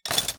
Add toolbox sound effects
toolbox_insert.ogg